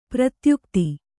♪ pratyukti